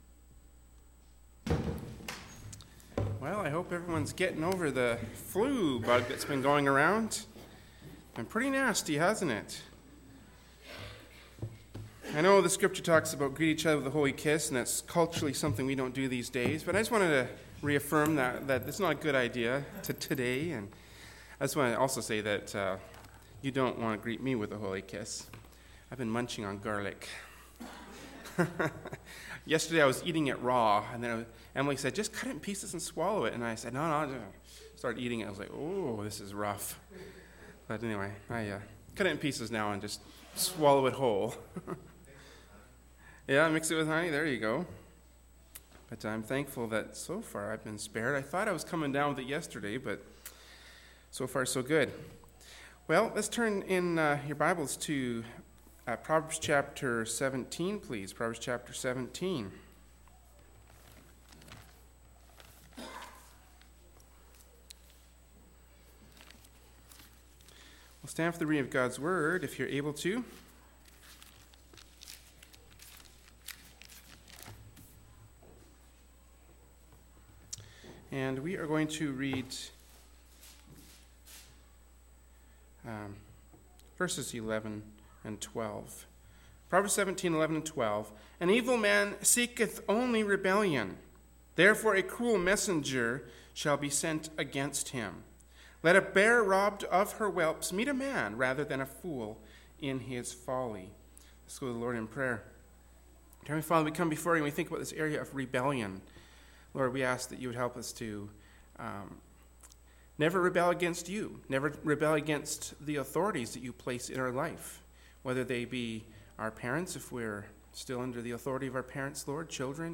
“Proverbs 17:11-12” from Sunday School Service by Berean Baptist Church.